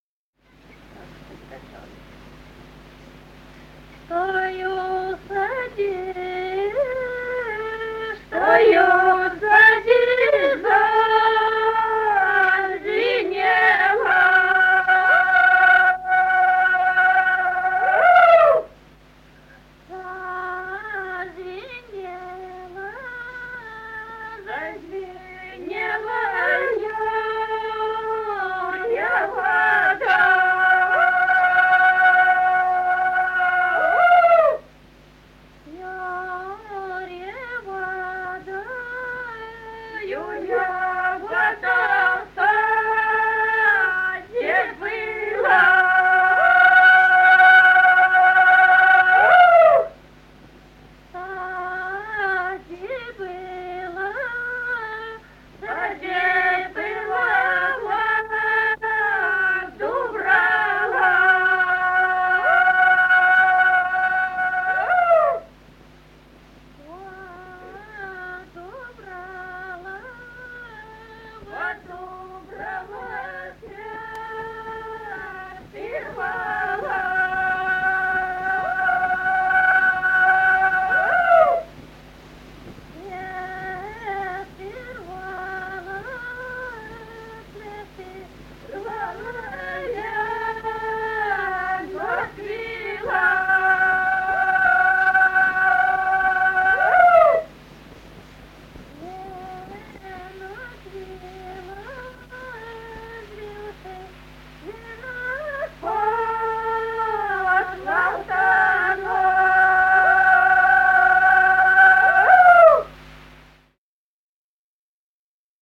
Народные песни Стародубского района «Чтой у саде зазвенело», юрьевская таночная.
1959 г., с. Курковичи.